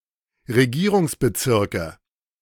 Regierungsbezirke (plural, pronounced [ʁeˈɡiːʁʊŋsbəˌtsɪʁkə]
De-Regierungsbezirke.ogg.mp3